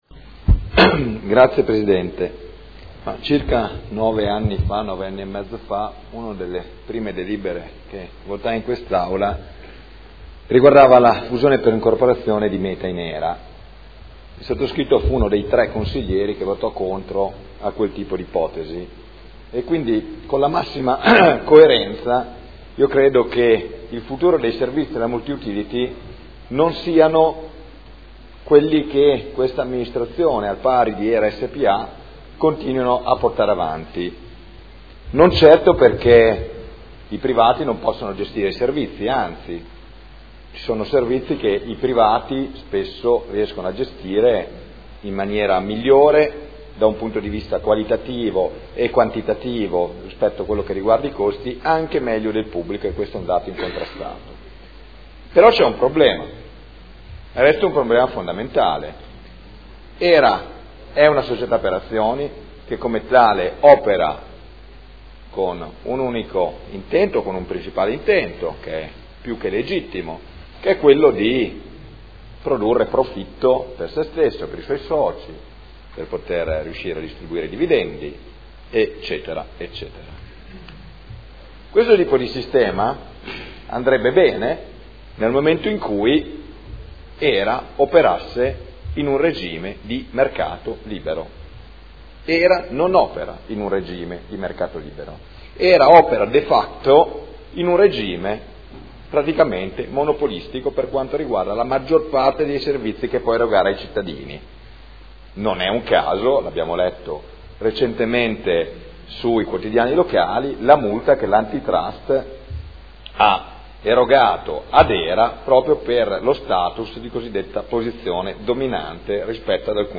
Michele Barcaiuolo — Sito Audio Consiglio Comunale
Proposta di deliberazione: Fusione per incorporazione di AMGA Azienda Multiservizi di Udine in Hera e modifiche allo Statuto Hera. Dibattito